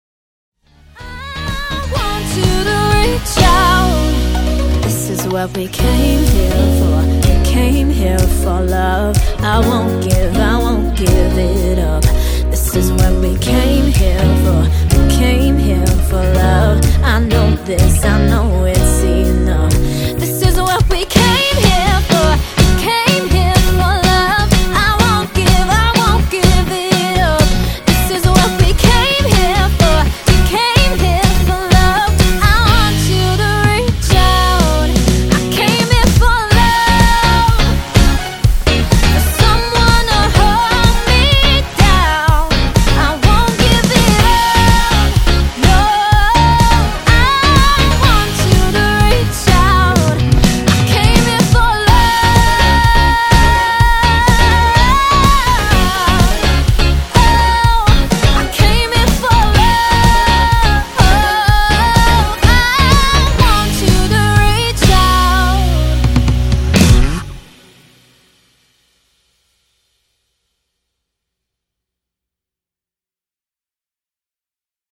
Modern London Function Band with Female Vocals